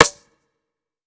Soulful Snare.wav